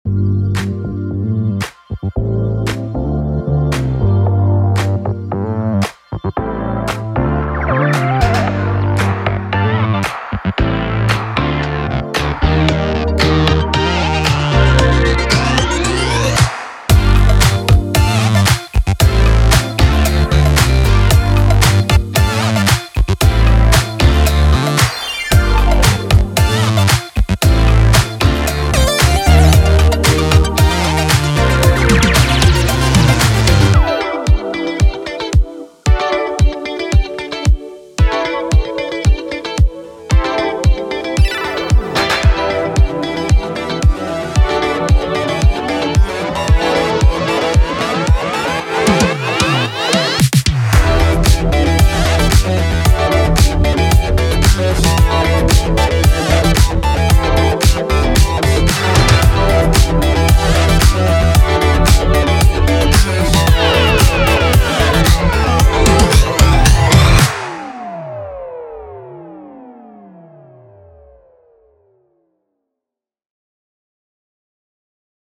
这个素材包里有超带感的吉他、动感的合成器和让人忍不住跟着摇摆的鼓点节奏，能帮你实现做流行音乐的梦想。